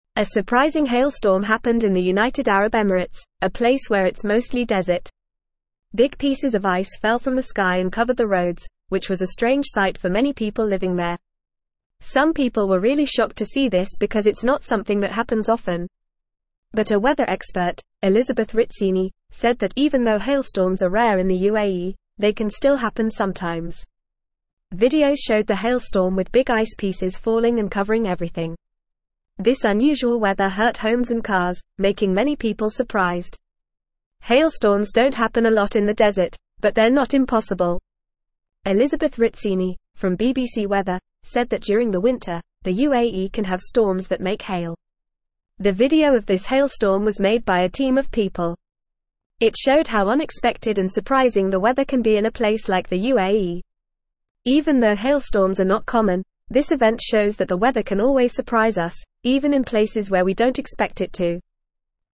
Fast
English-Newsroom-Lower-Intermediate-FAST-Reading-Ice-Storm-in-United-Arab-Emirates-A-Surprising-Weather-Event.mp3